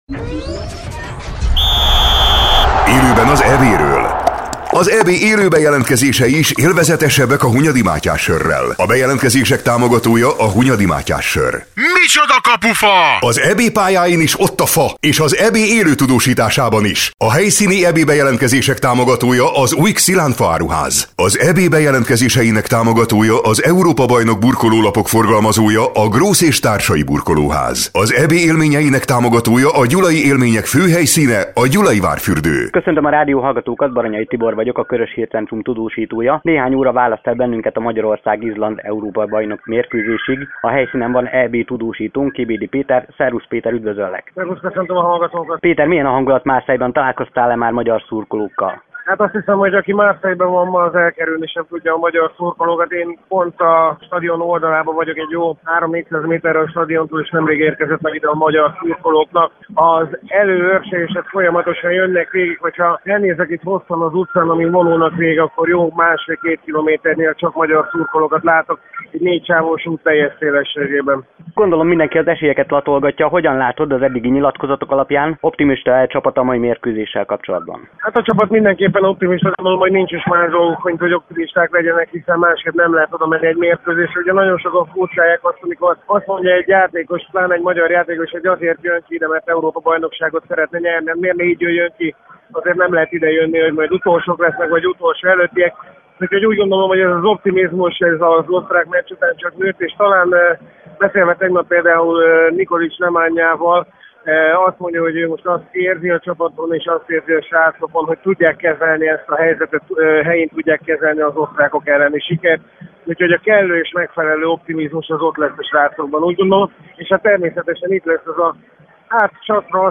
Már csak percekben mérhető az idő a mérkőzés kezdetéig. Kiküldött tudósítónk ezúttal Marseille-ből jelentkezett, és több tízezres magyar szurkolói létszámról számolt be a helyszínen.